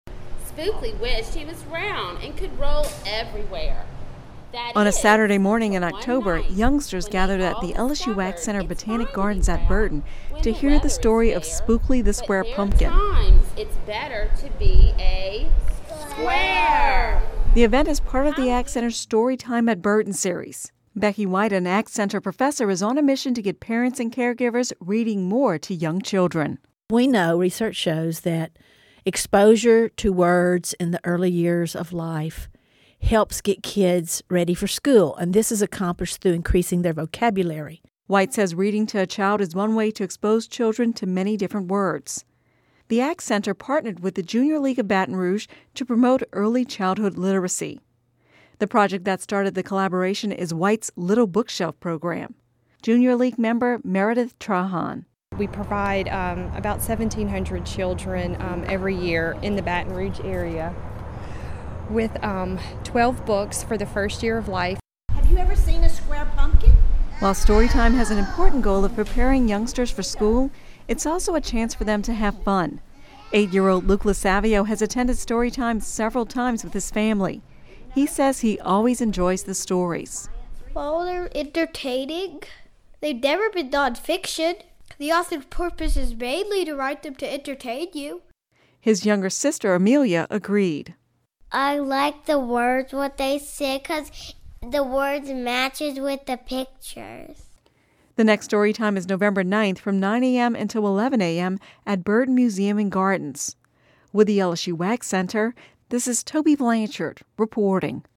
(Audio News 11/06/13) On a Saturday morning in October, youngsters gathered at the LSU AgCenter Botanic Gardens at Burden to hear the story of Spookly the Square Pumpkin.